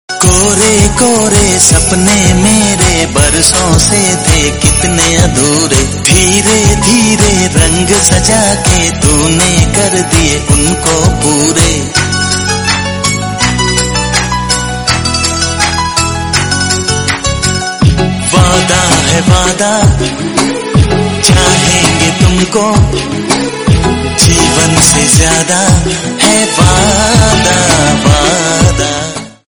Bollywood 4K Romantic Song